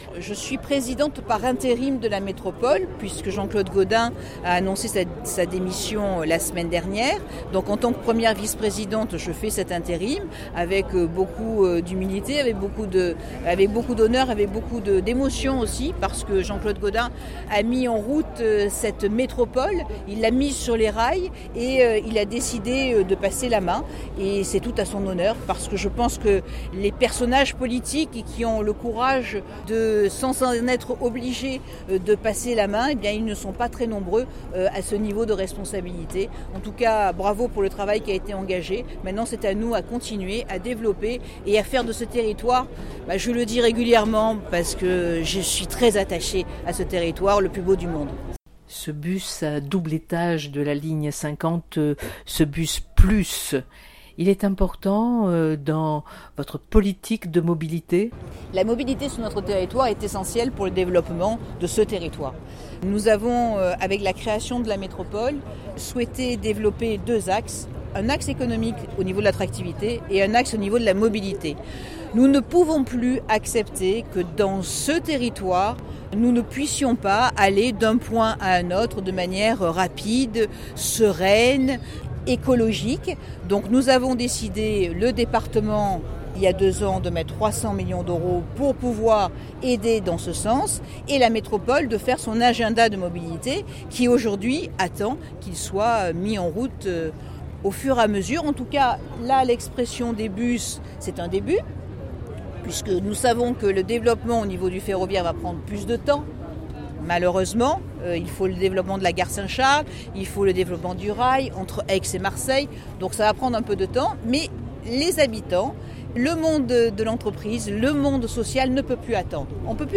Entretien.
martine_vassal_bus_12_09_2018.mp3